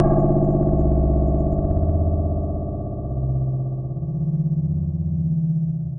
科学幻想无人机 " 无人机06
描述：科幻无人机，用于室内或室外房间的音调，气氛，外星人的声音，恐怖/期待的场景.
Tag: 外来 大气压 无人驾驶飞机 科幻 恐怖 roomtones SCI